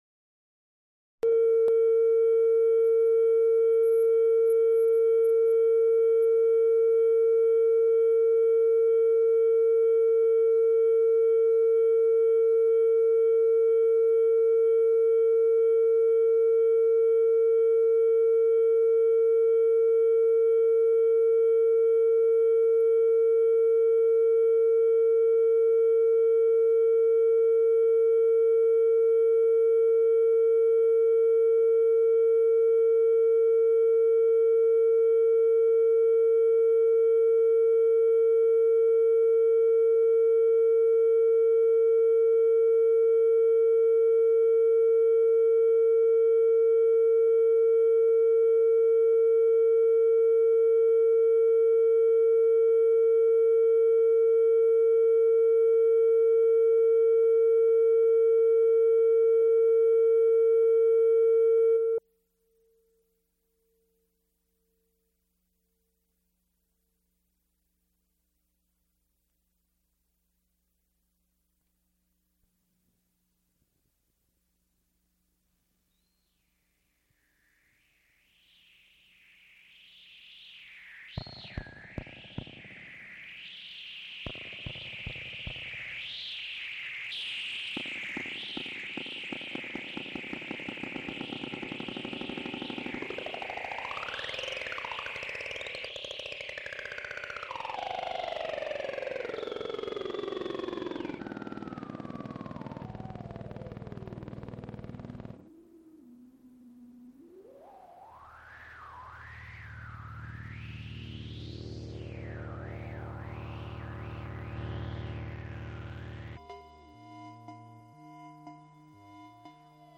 Recorded December 13, 1976, Room 123, Music Bldg., University of Pittsburgh.
musical performances
Electronic music